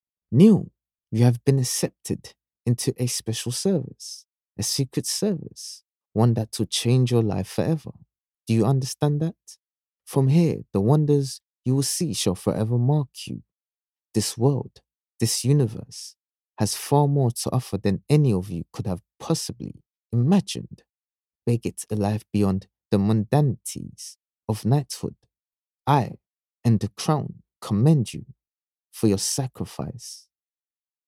British Voice Over Artists, Talent & Actors
English (Caribbean)
Yng Adult (18-29) | Adult (30-50)